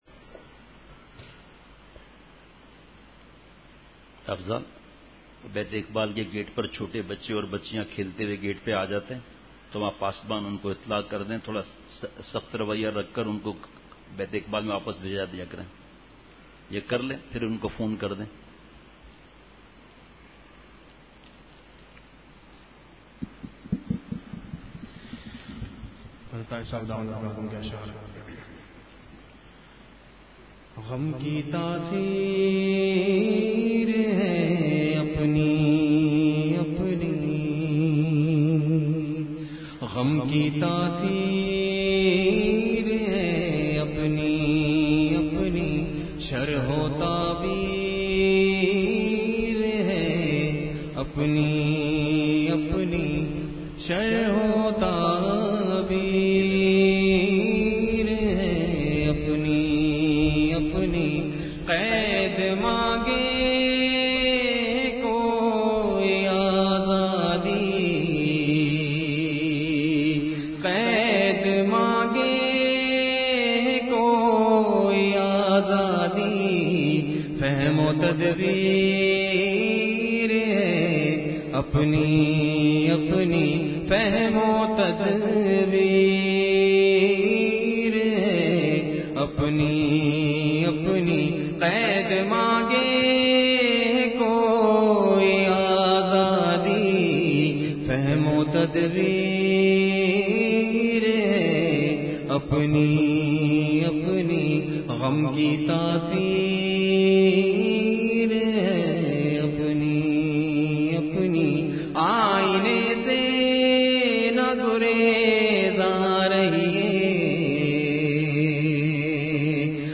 مرکزی بیان ۱۱ جولائی ۲۰۱۹ء : اسلامی معاشرے میں اخلاق کی اہمیت !